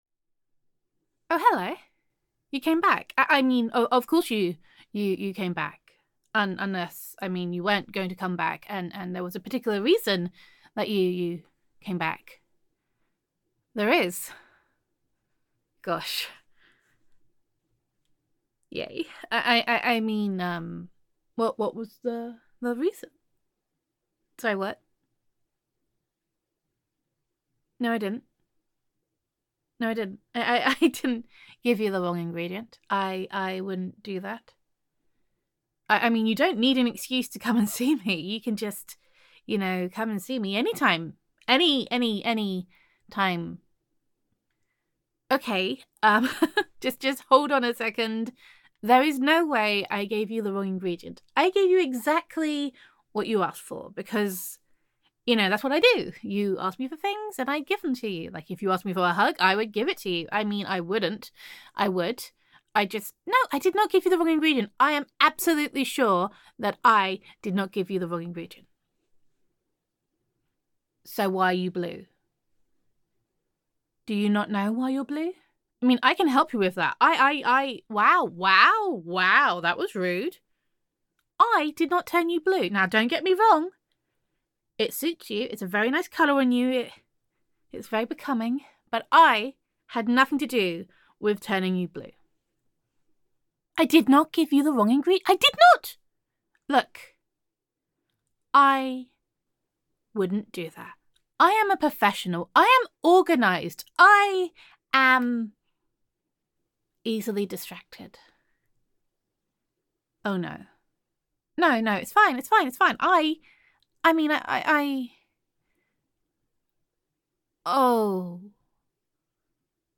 [F4A] Too Distracting [Oops][Too Busy Looking at Your Arse to Do My Job][Blue Looks Good on You][Disorganized Apothecary][Gender Neutral][You Are Awfully Pretty So It Is Kind of Your Fault That You Turned Blue]